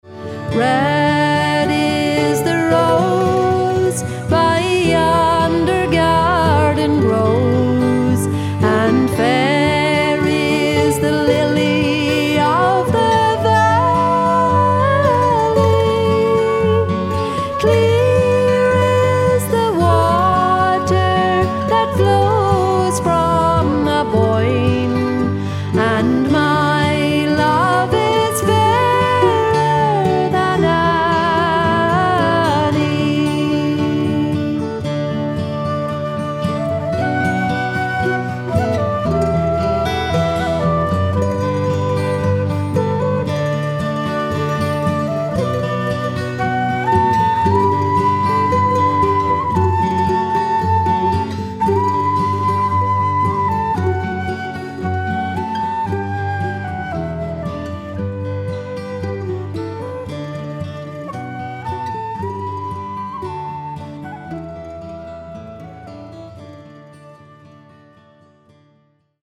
Harp and Flute
Rhythm guitar
Mandolin